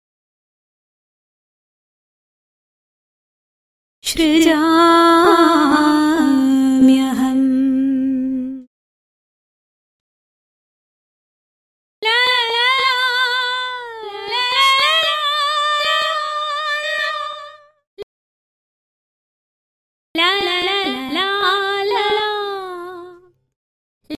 Merkwürdige Loops
Die klingen teilweise kaputt, defekt.
Sie klingen zerstückelt, zerhackt.